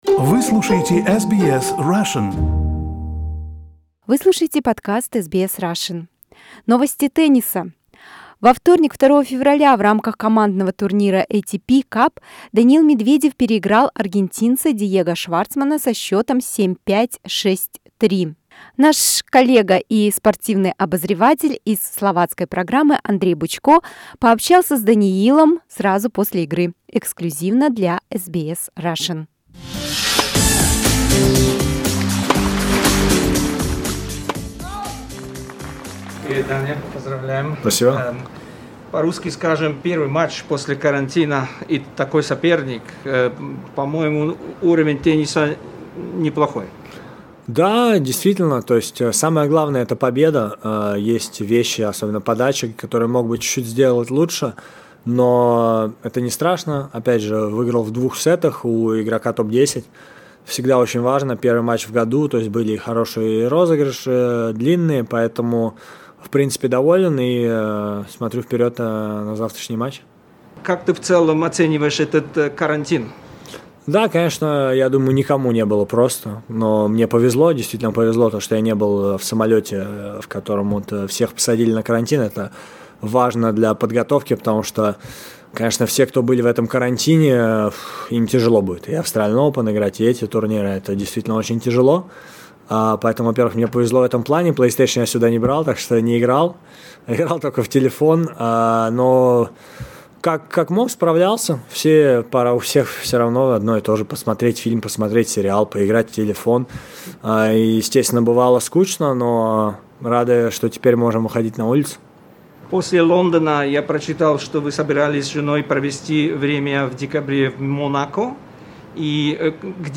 Во вторник, 2 февраля, в рамках командного турнира ATP Cup Даниил Медведев переиграл аргентинца Диего Шварцмана со счётом 7:5, 6:3. Интревью с Даниилом после поединка - эксклюзивно для SBS Russian.